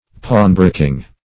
Pawnbroking \Pawn"bro`king\, n. The business of a pawnbroker.